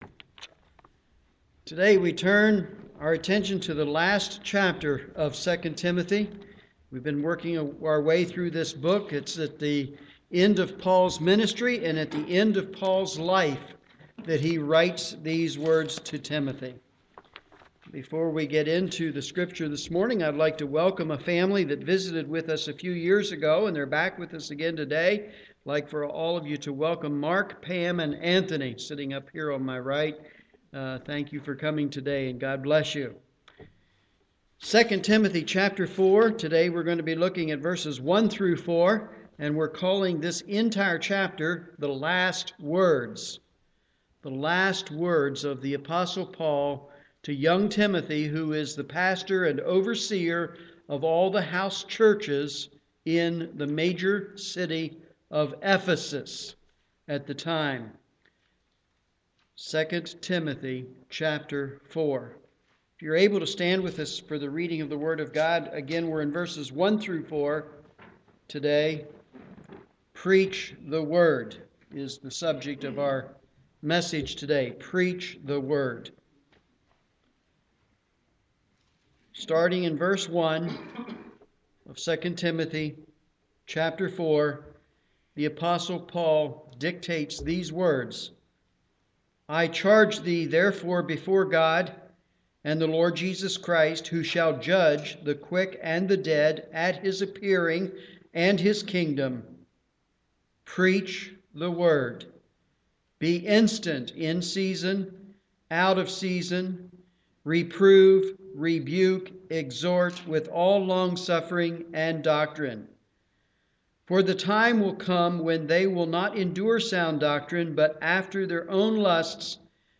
Morning Service
Sermon